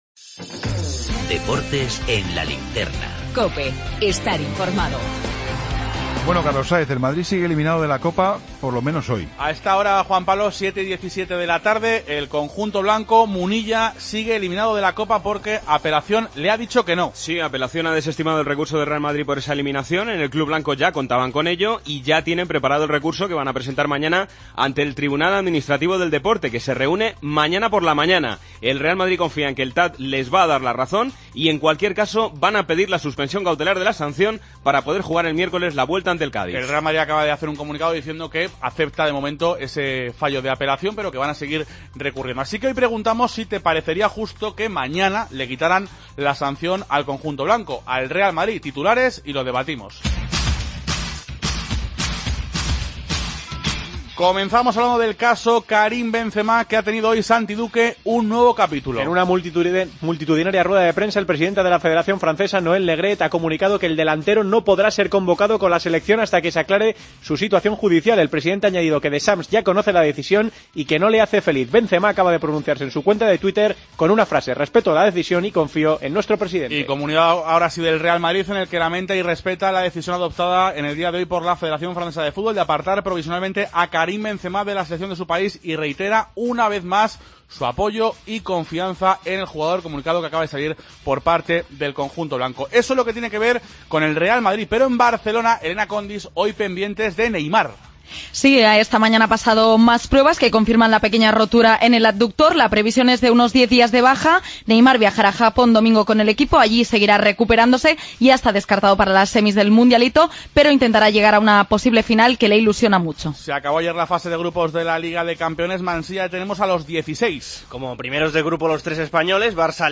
El debate